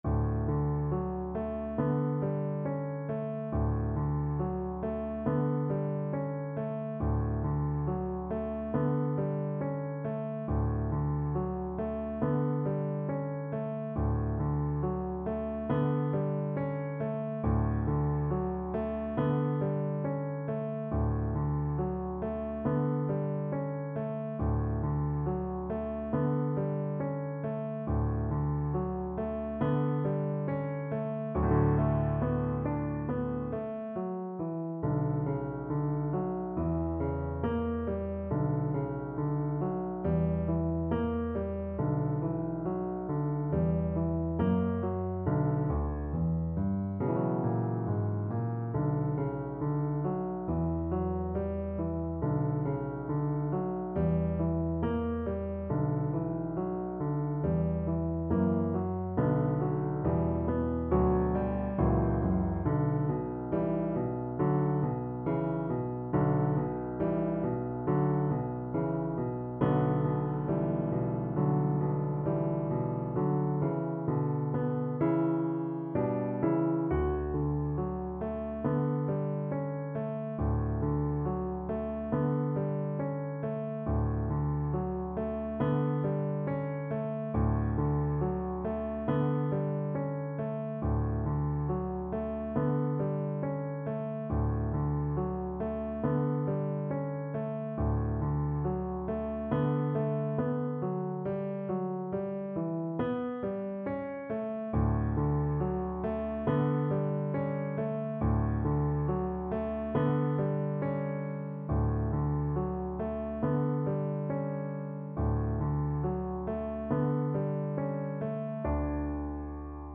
~ =69 Poco andante
4/4 (View more 4/4 Music)
Classical (View more Classical Viola Music)